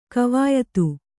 ♪ kavāyatu